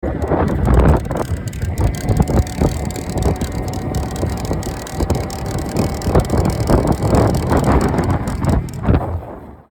S-Mag clicking sound
After 4.500km my Brose S-Mag suddenly started to make a continues clicking sound on my Specialized Kenvo (2021). The sound is similar to a chain guide which is not well adjusted (listen to the attached mp3 file). The sound is louder the higher the motor support is adjusted.